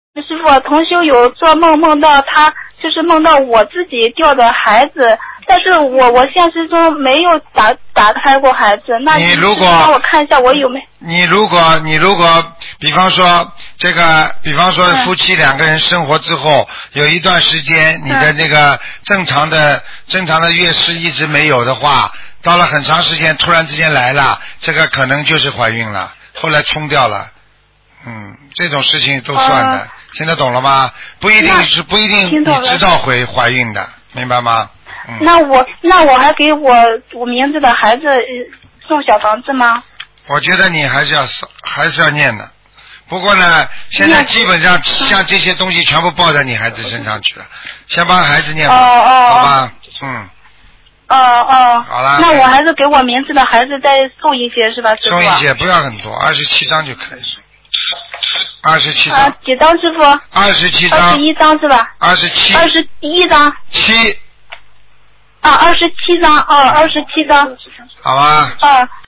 女听众：